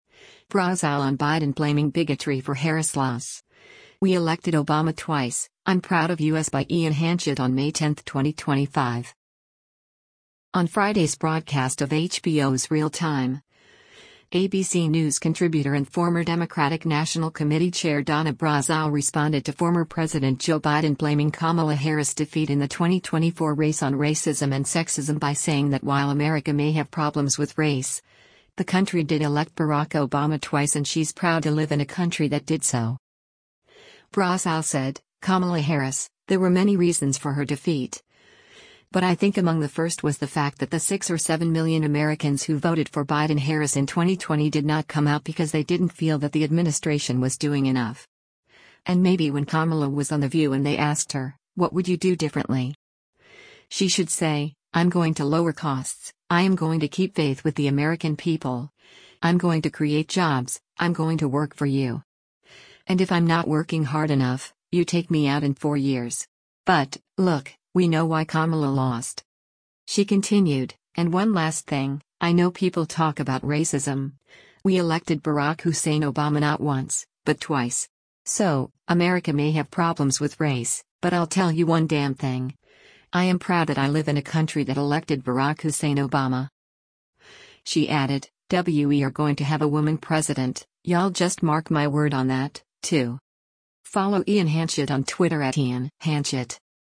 On Friday’s broadcast of HBO’s “Real Time,” ABC News Contributor and former Democratic National Committee Chair Donna Brazile responded to former President Joe Biden blaming Kamala Harris’ defeat in the 2024 race on racism and sexism by saying that while “America may have problems with race,” the country did elect Barack Obama twice and she’s “proud” to live in a country that did so.